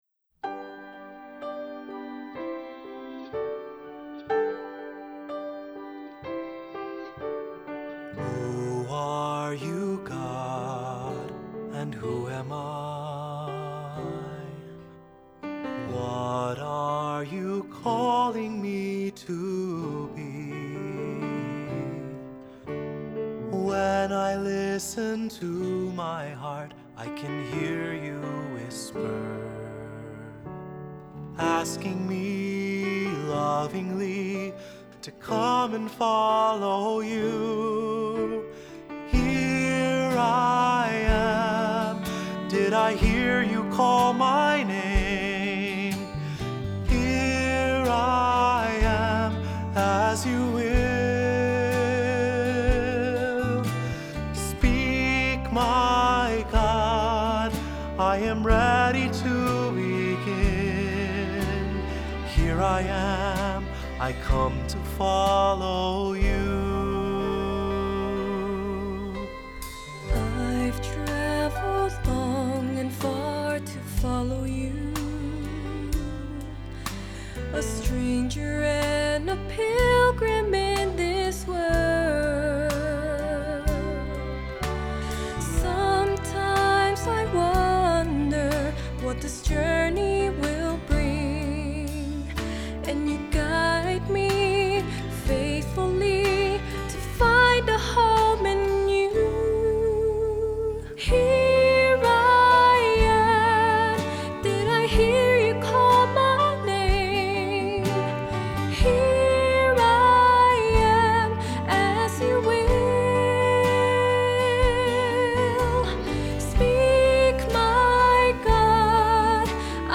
Accompaniment:      Keyboard
Music Category:      Christian
String quartet is optional